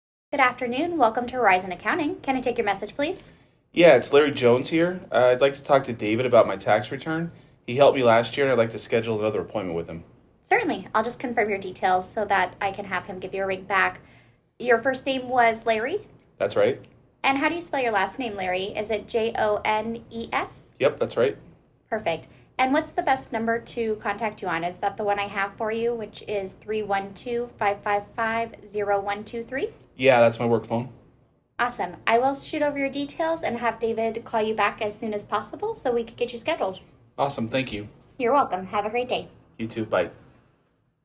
phone-message-answering-service-call-sample-MessageExpress.mp3